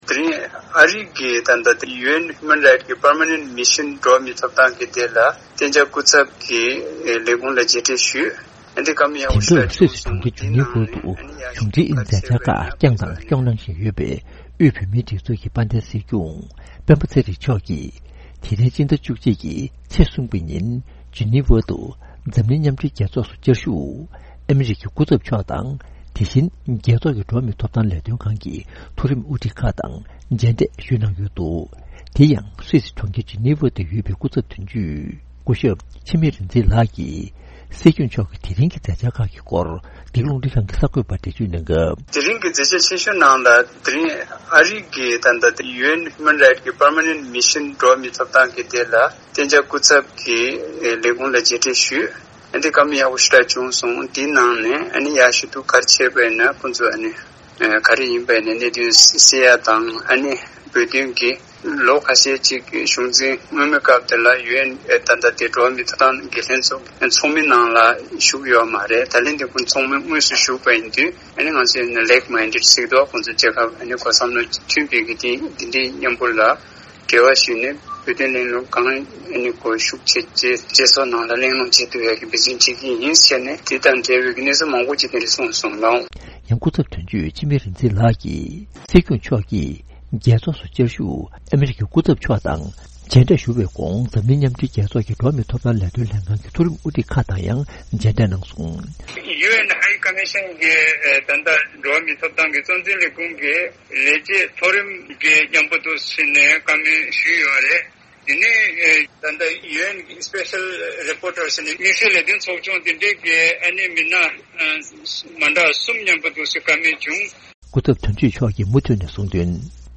བཅར་འདྲི་ཞུས་ནས་གནས་ཚུལ་